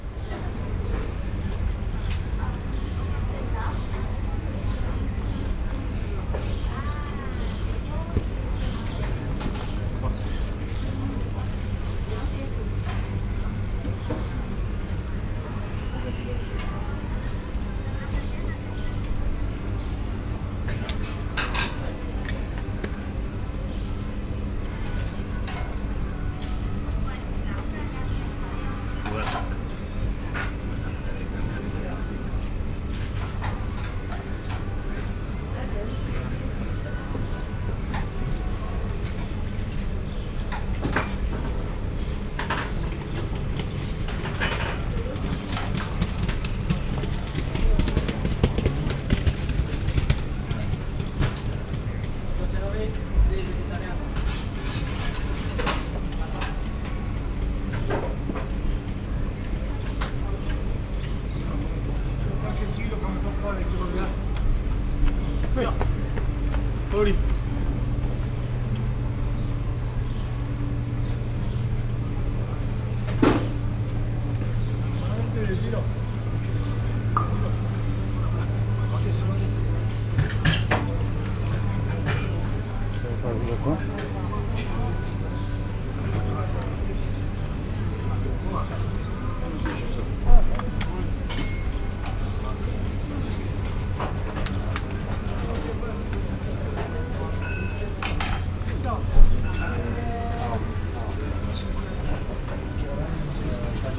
kavezoelott_guruloskocsifutoember_velence_omni_sds01.43.WAV